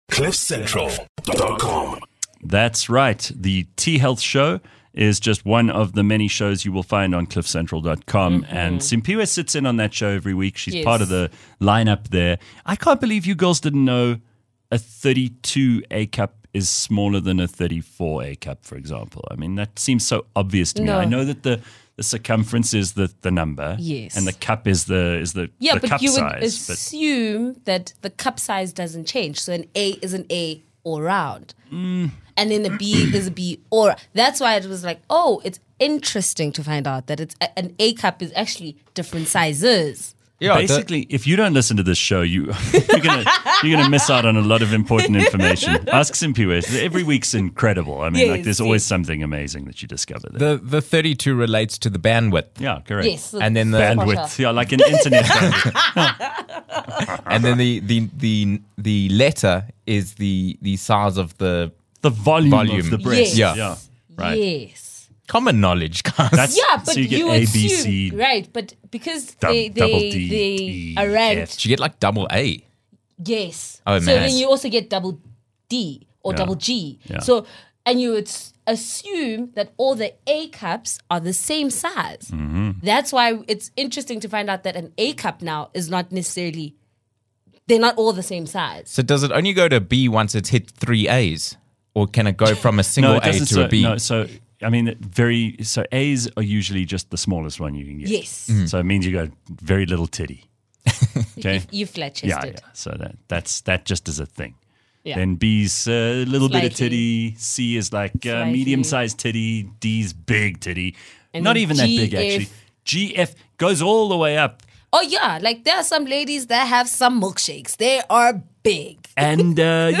A hybrid of online radio, podcast and YouTube – live from 06h00 to 08h00 – Gareth and his team bring you ‘unradio’ – unscripted, uncensored, real conversations about everything that happens in our world, everything we all experience every day, what makes us think… makes us laugh… makes us cry… makes us angry… inspires us… and makes us human. It’s about the stuff that connects us on this journey called LIFE.